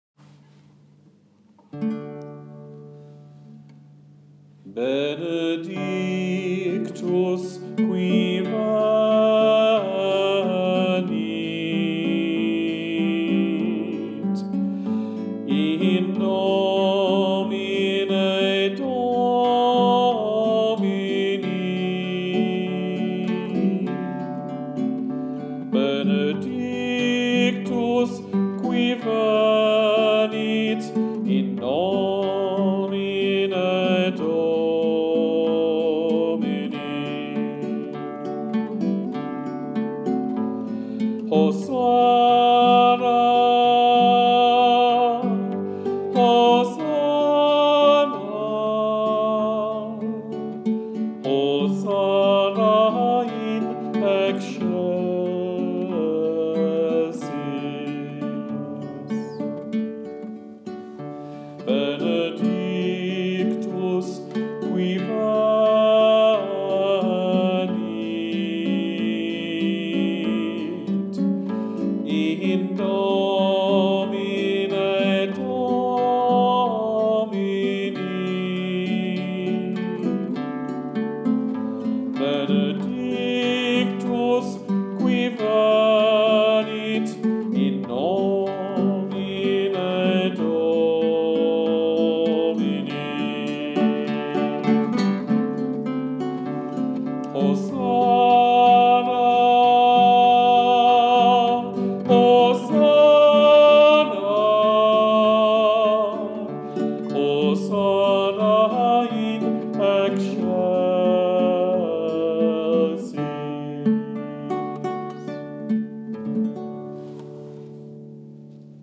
Latin hymn